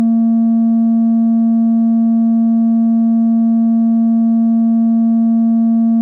Arp 2600正弦波220hz.